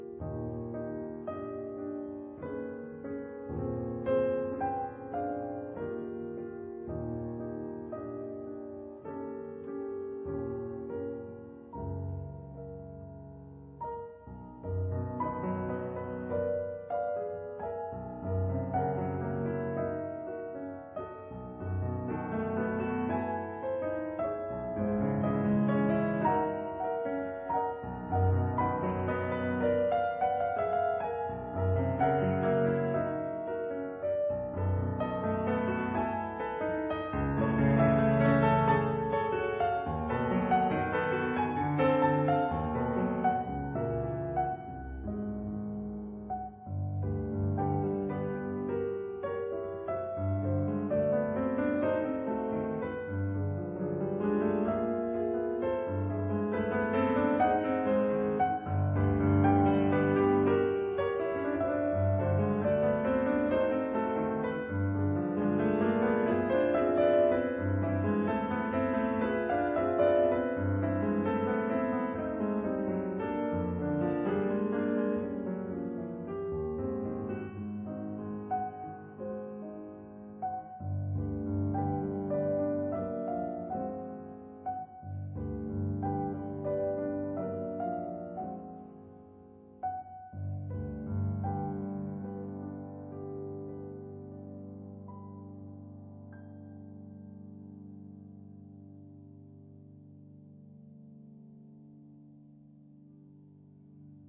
他的音色相當乾淨，有種去除了雜質後的純淨。